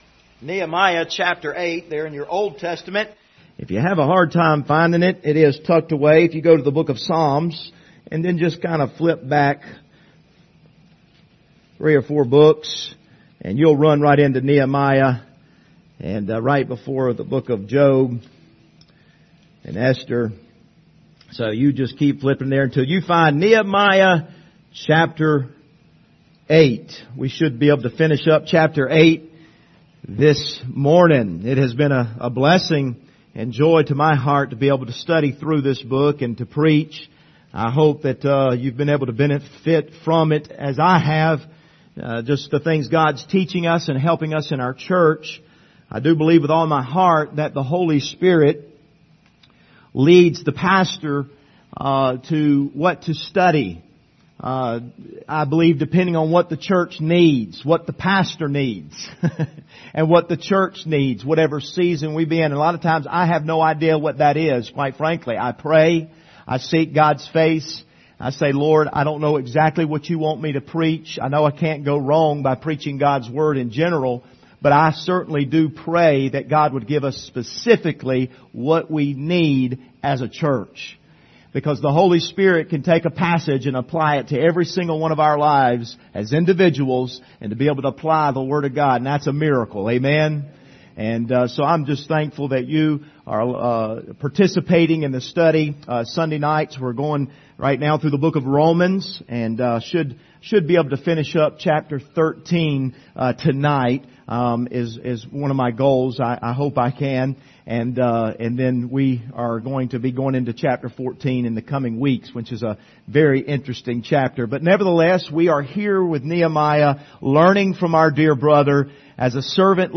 Passage: Nehemiah 8:13-18 Service Type: Sunday Morning